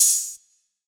Open Hats
Oh (Trap).wav